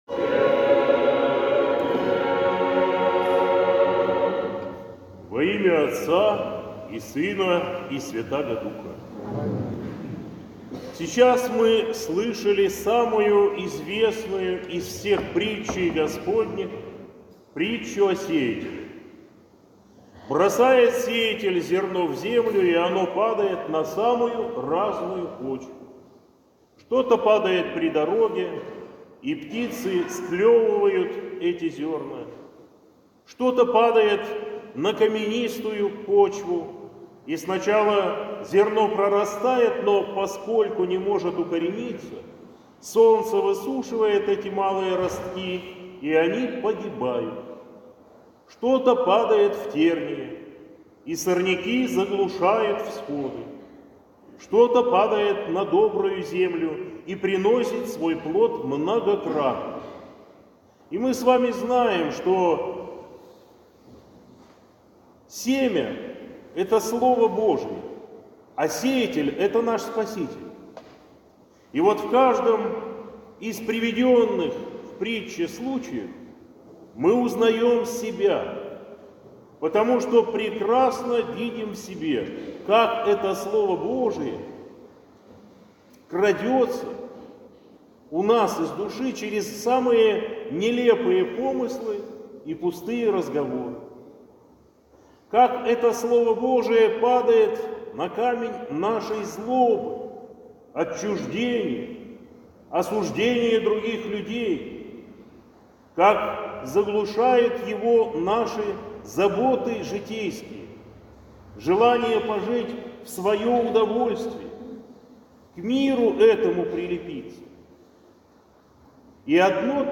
Проповедь